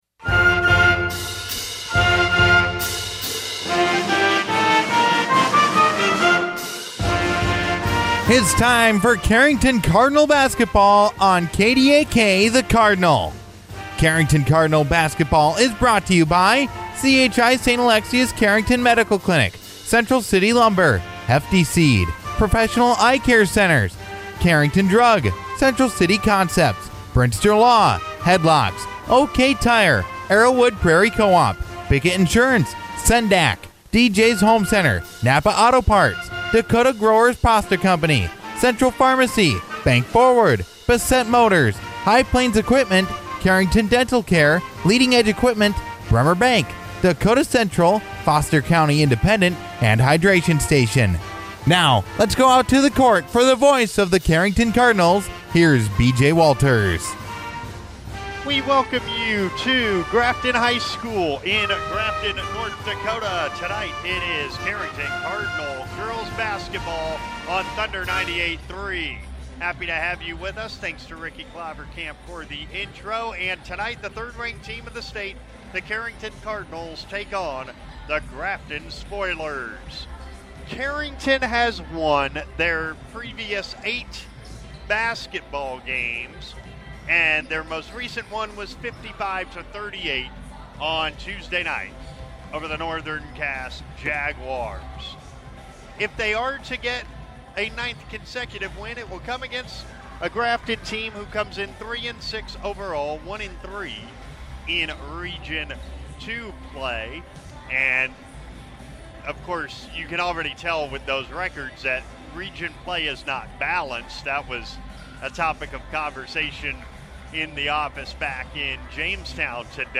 Fortunately for the Cardinals, they had enough offense and more masterful defense to lean on in a 49-30 Region II road win over the Grafton Spoilers on Thursday evening at the Grafton Performing Arts Center.
chs-gbb-at-grafton.mp3